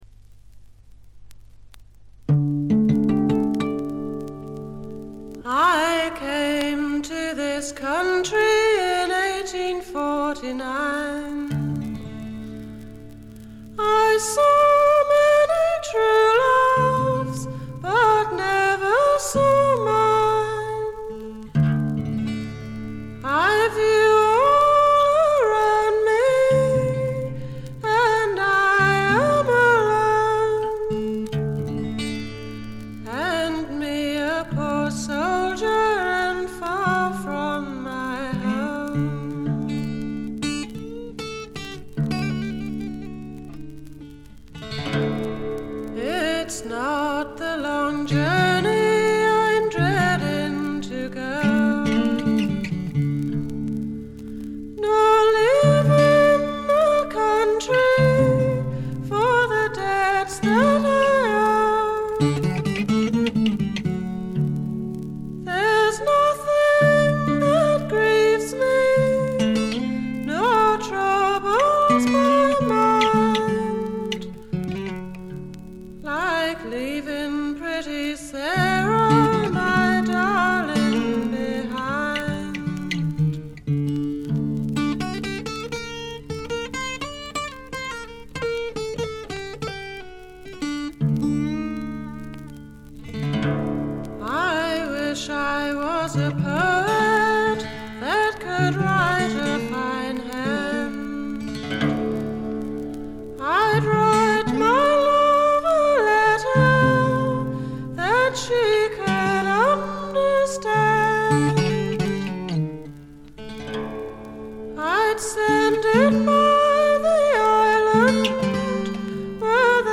バックグラウンドノイズ、軽微なチリプチが聞かれはしますがほとんど気にならないレベルと思います。
試聴曲は現品からの取り込み音源です。